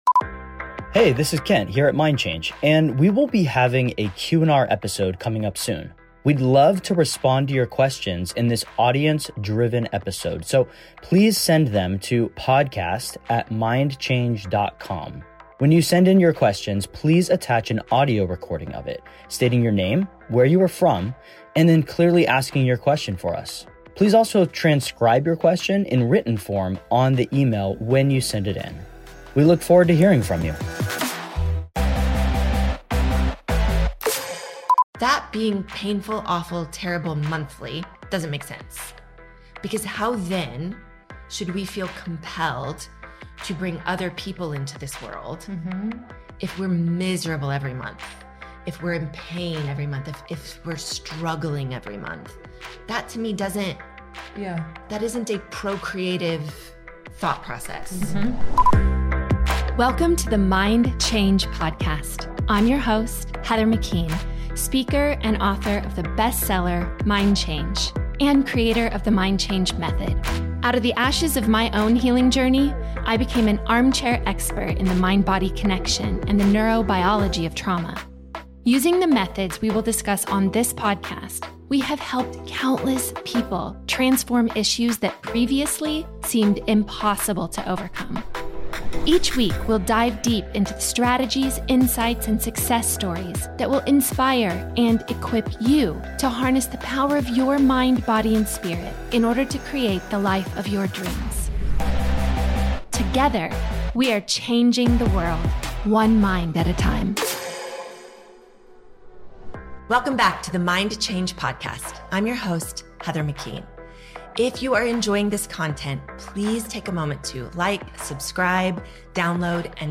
an honest, hopeful roundtable